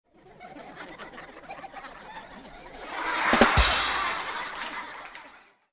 AA_heal_telljoke.ogg